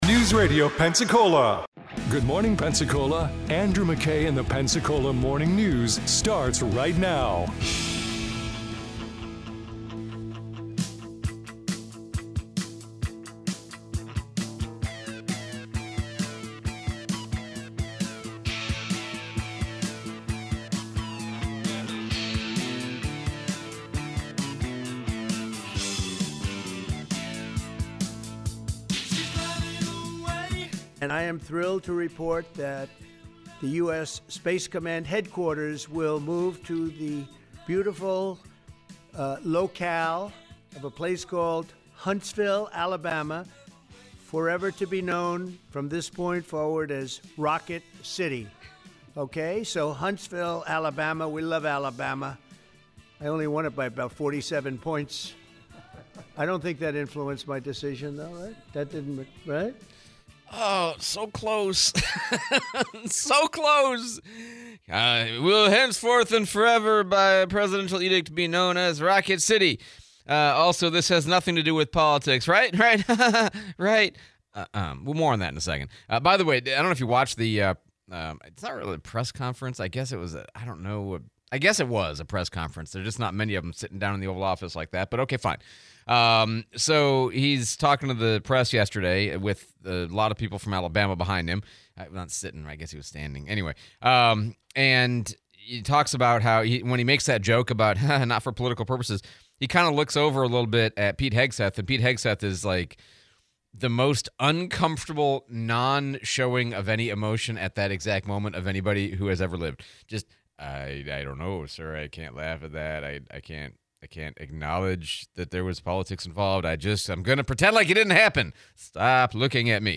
Trump's press conference, interview with Sheriff Simmons